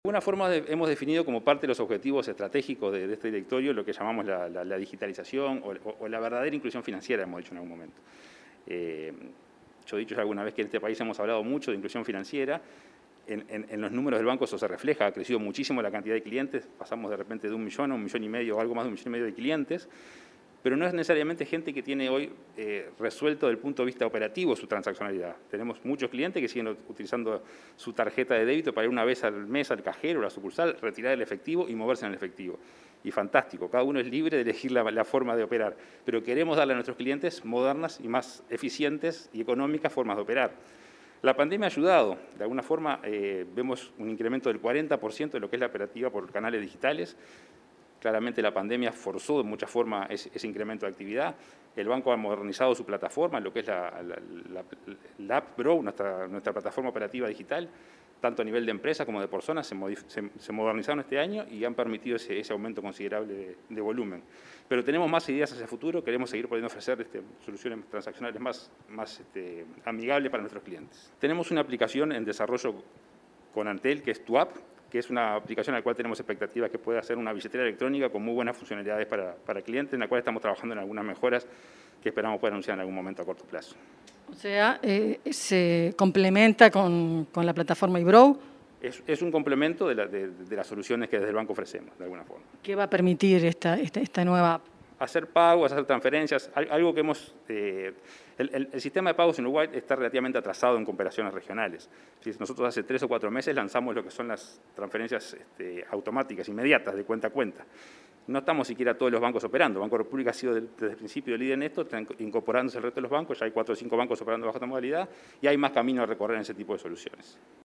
Entrevista al presidente del BROU, Salvador Ferrer